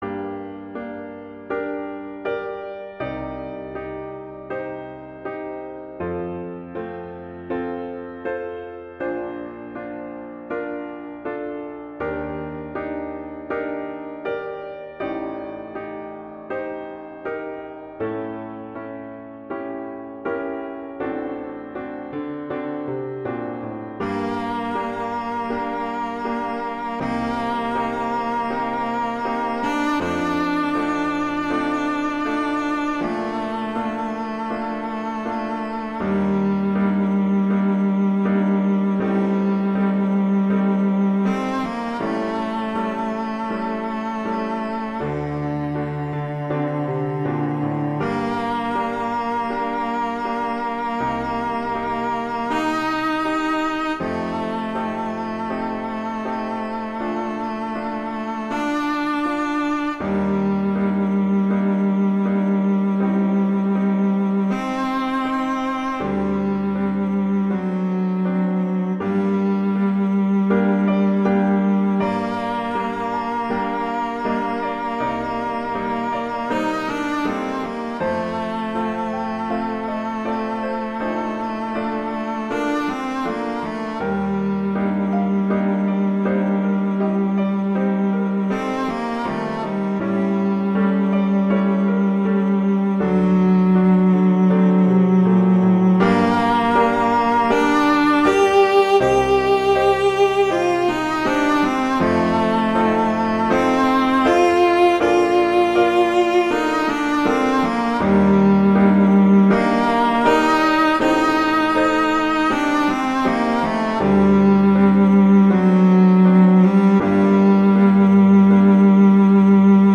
classical, wedding, traditional, festival, love
G minor
♩=80 BPM